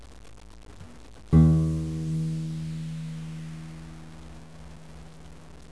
Tuning the Guitar
1. The big fat string should be on top and should sound like
string6.wav